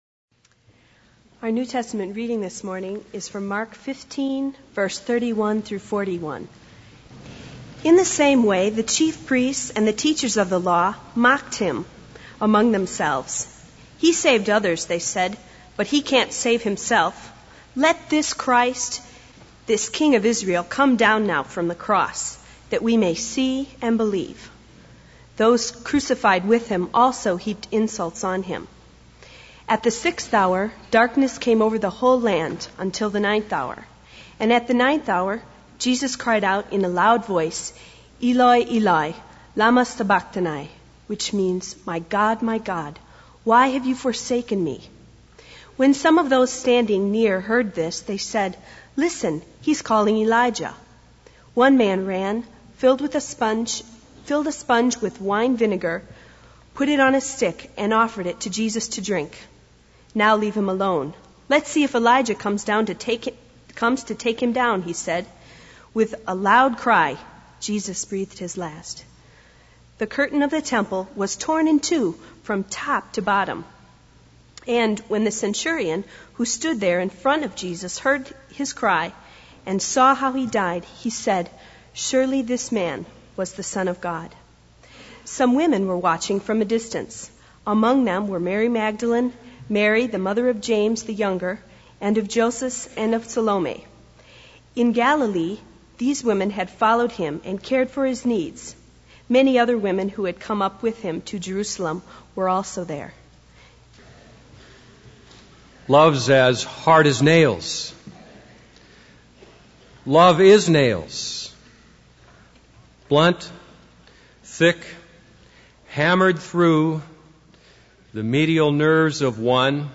This is a sermon on Mark 15:31-41.